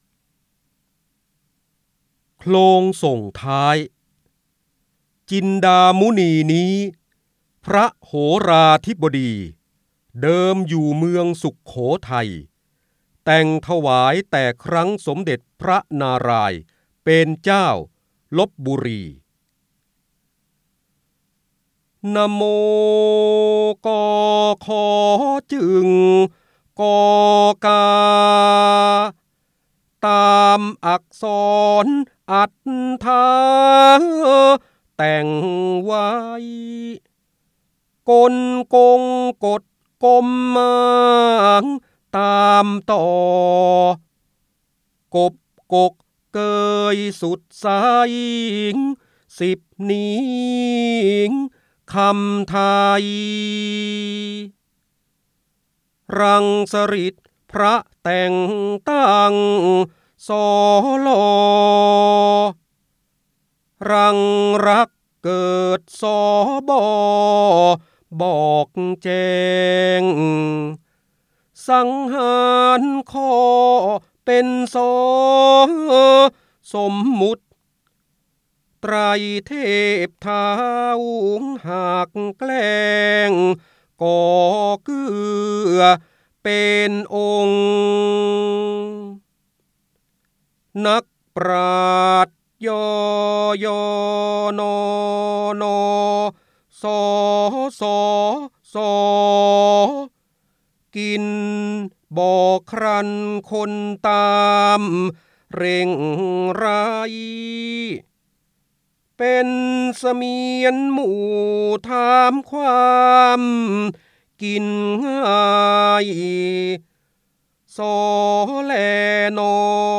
66 67 ตัวอย่าง ดาวน์โหลด ส่ง eCard เสียงบรรยายจากหนังสือ จินดามณี (พระโหราธิบดี) โคลงสงท้าย ได้รับใบอนุญาตภายใต้ ให้เผยแพร่-โดยต้องระบุที่มาแต่ห้ามดัดแปลงและห้ามใช้เพื่อการค้า 3.0 Thailand .